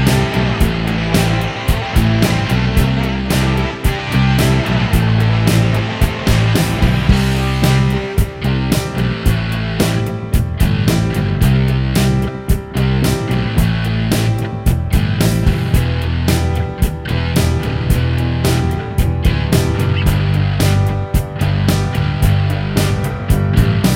Two Semitones Down Pop (1980s) 4:06 Buy £1.50